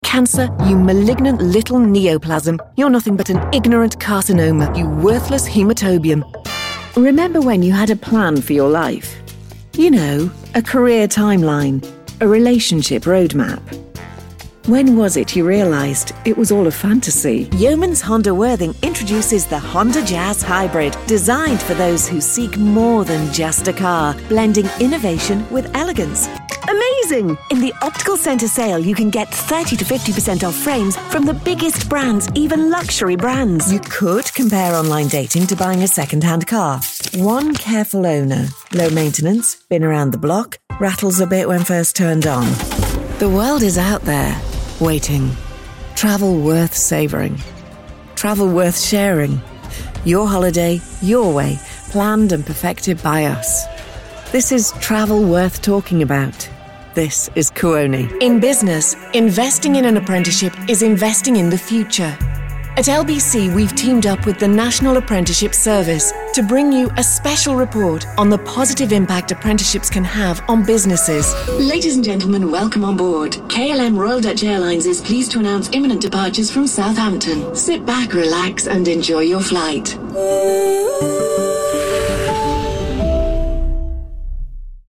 Kommerzielle Demo
Englisch (Britisch)
Im mittleren Alter
Altstimme
KlarVertrauenswürdigWarm